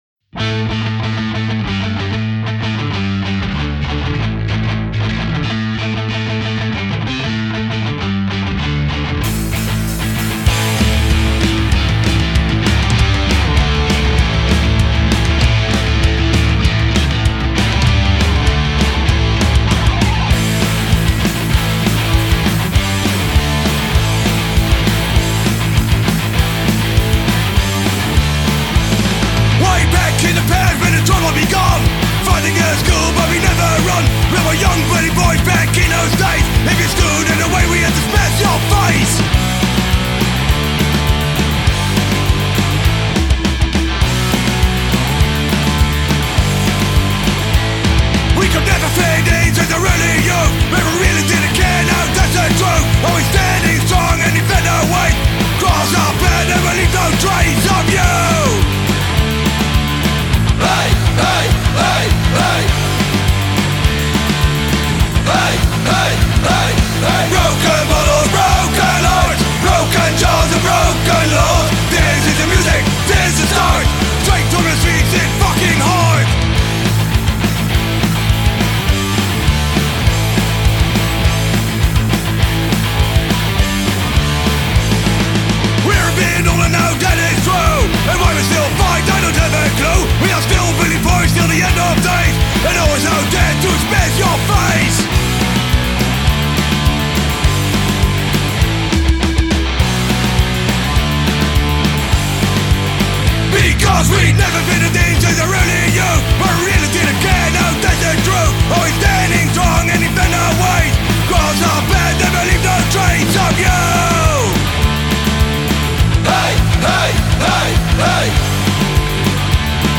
Hooligan-Oi-Band
Schön flotter Hool-Oi-Rock mit ordentlich Melodie im Gepäck.